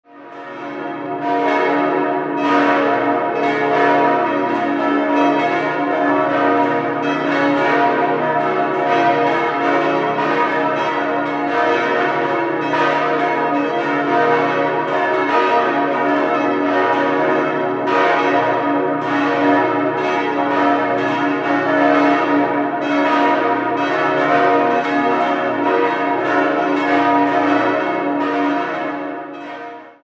Friedensglocke b° 2.250 kg 1958 Georg Hofweber, Regensburg Michaelsglocke des' 1.600 kg 1958 Georg Hofweber, Regensburg Theresienglocke es' 1.050 kg 1951 Petit&Edelbrock, Gescher Marienglocke f' 650 kg 1913 Karl Hamm, Regensburg Josefsglocke as' 400 kg 1913 Karl Hamm, Regensburg Siegfrieds- und Messglocke b' 250 kg 1951 Petit&Edelbrock, Gescher